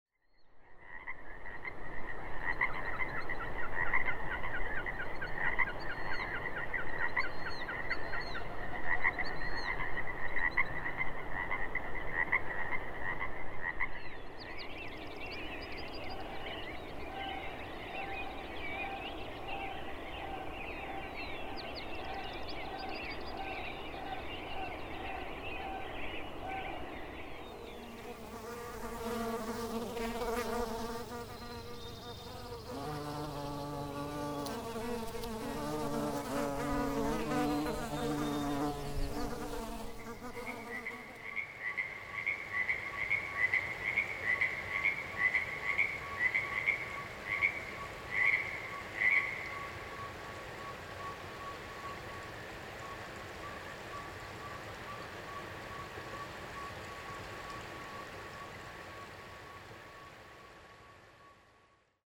Re: bee sounds
The third segment in there has sounds of bumble bees on a blooming currant bush
in the Ochoco Mountians of central Oregon. There may be two species there.